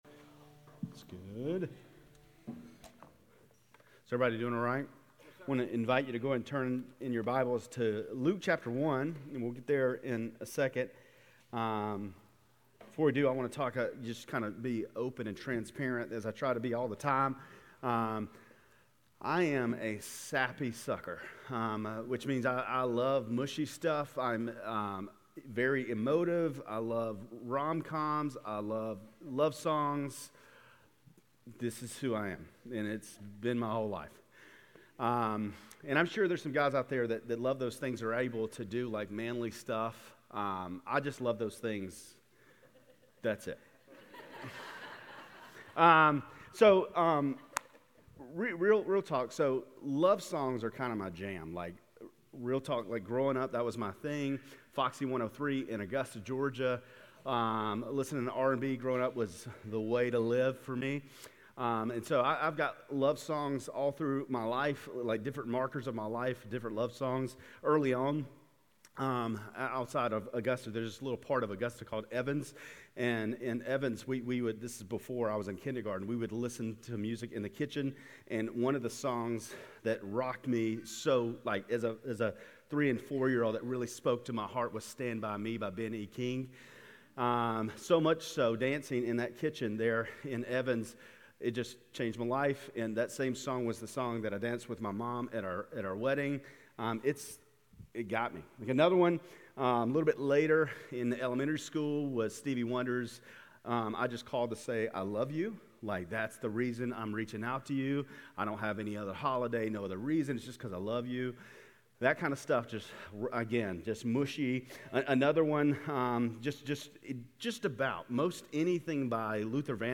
GCC-Lindale-November-26-Sermon.mp3